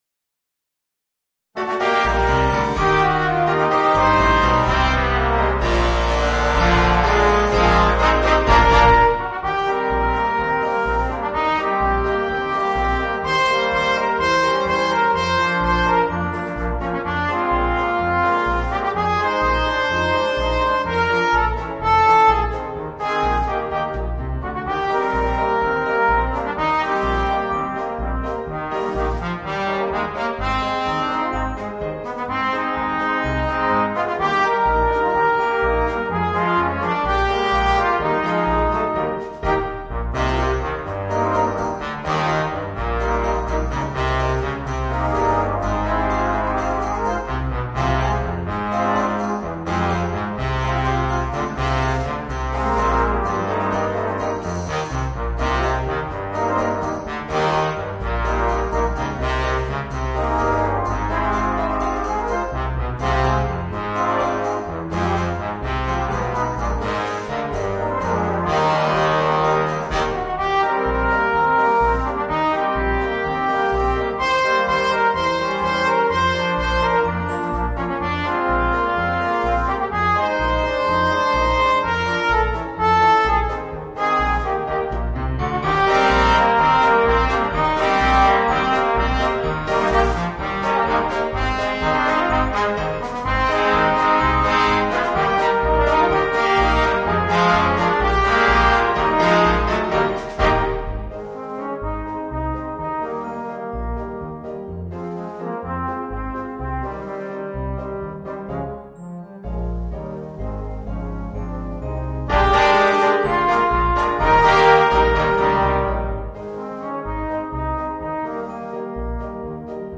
Voicing: 4 Trombones and Rhythm Section